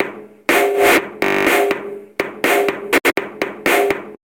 さらにディストーション系とローファイ系のVSTも掛けているので随分音が変わっています。
最初のサンプルを駆使した新たなフレーズ・サンプル（MP3）